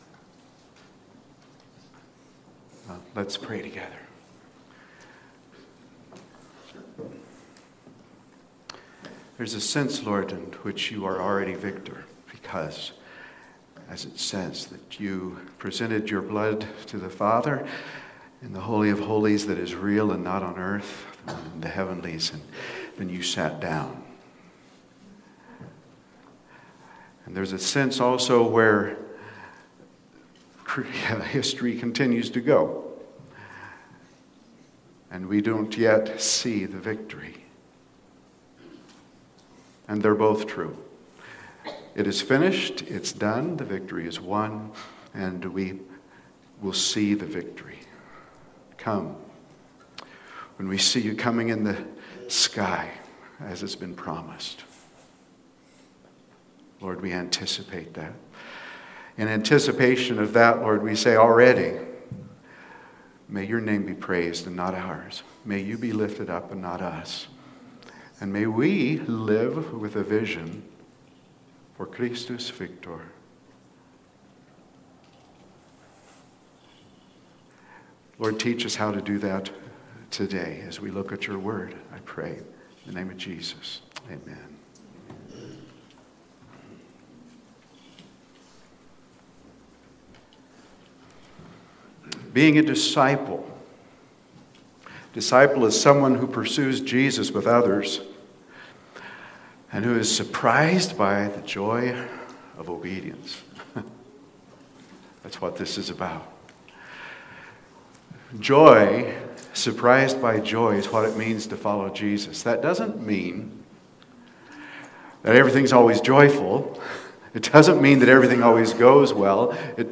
Sermon Prelude: Christus Victor (Amen) Audio (MP3) 28 MB PDF 228 KB Previous Is God Always Good?